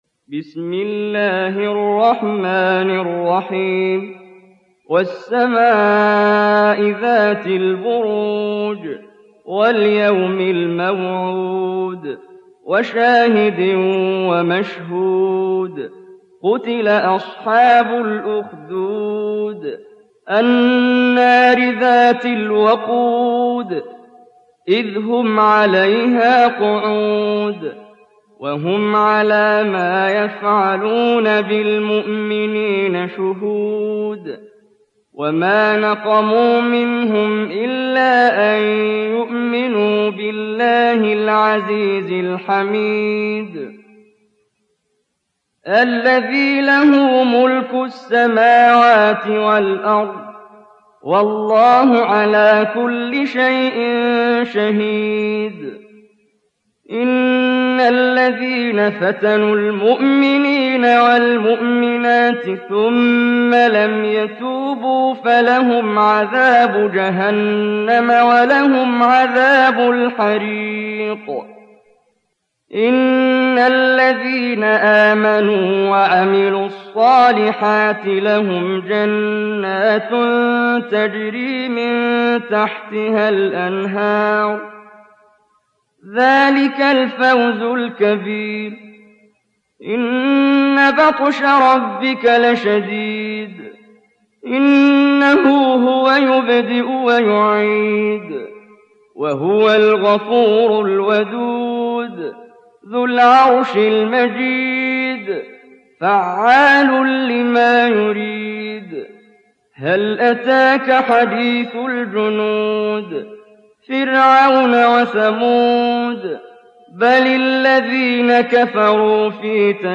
تحميل سورة البروج mp3 بصوت محمد جبريل برواية حفص عن عاصم, تحميل استماع القرآن الكريم على الجوال mp3 كاملا بروابط مباشرة وسريعة